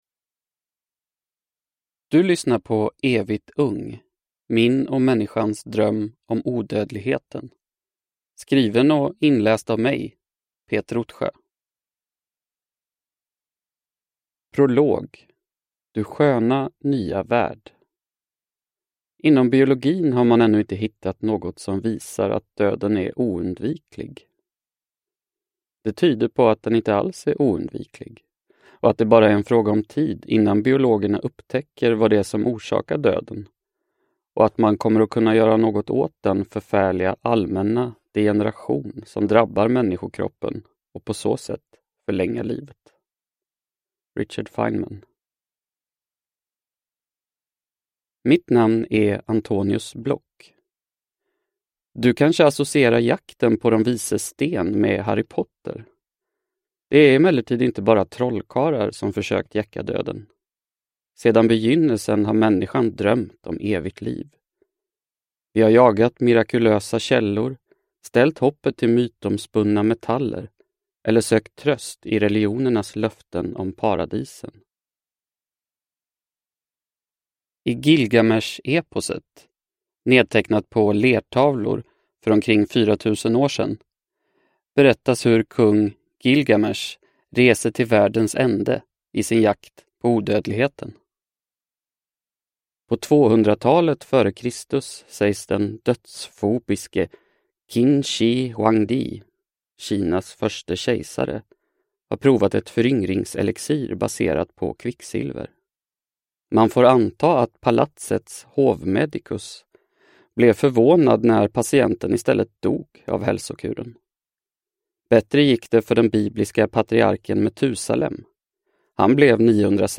Evigt ung : Min och människans dröm om odödligheten – Ljudbok – Laddas ner